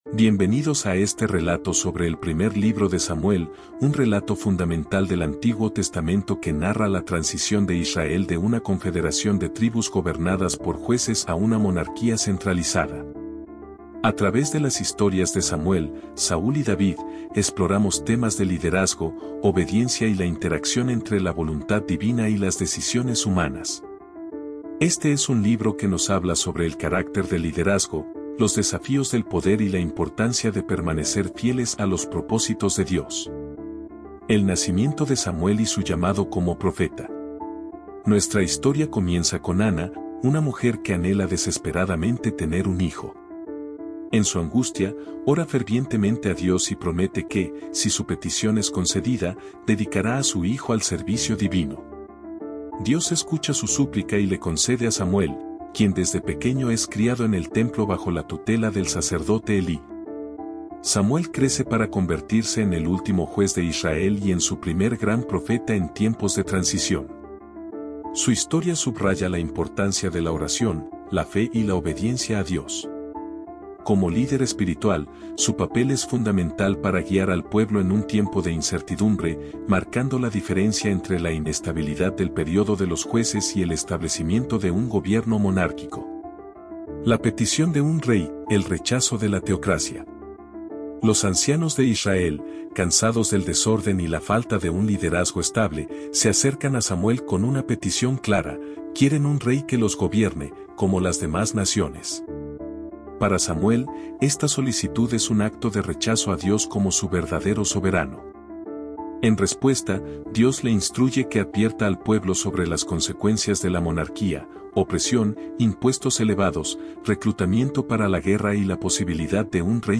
Audio Resumen